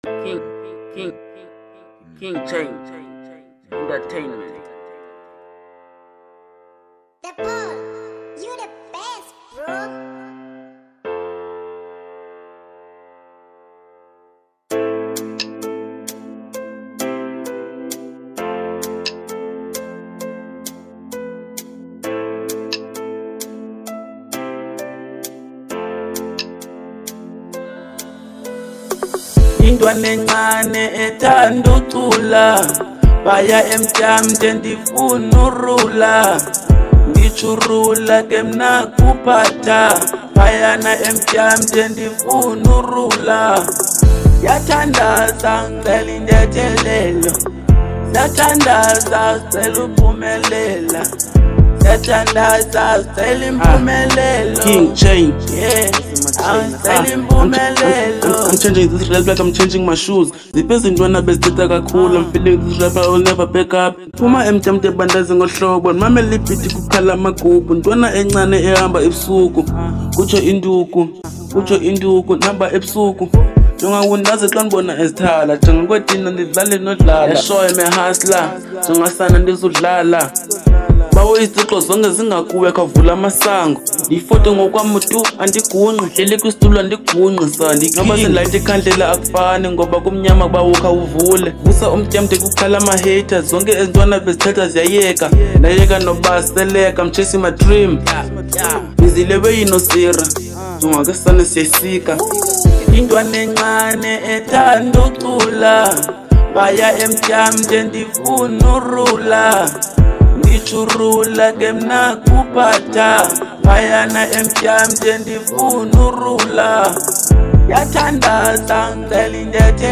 Genre : Trap